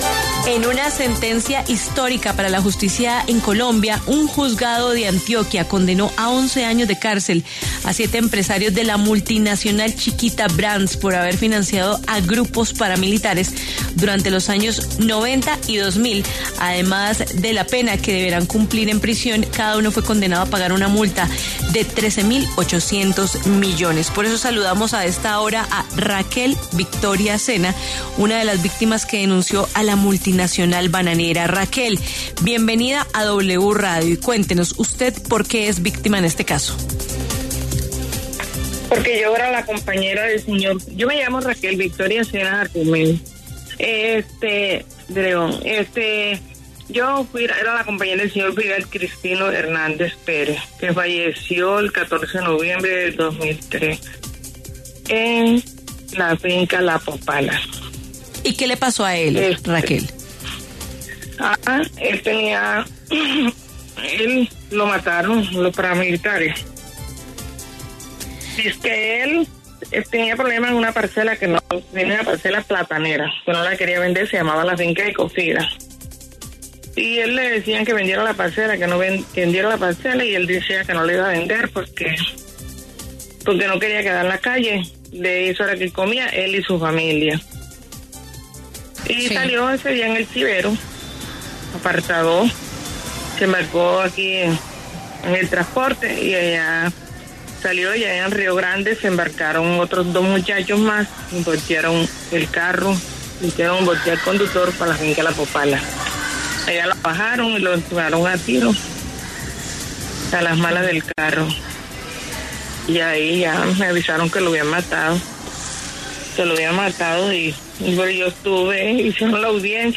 En diálogo con W Fin de Semana